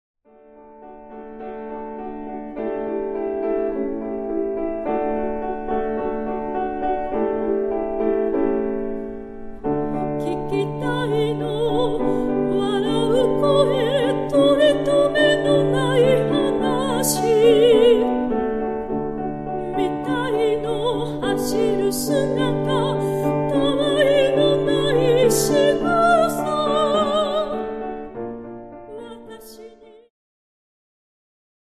ジャンル POPS系
癒し系
歌・ピアノ
シタール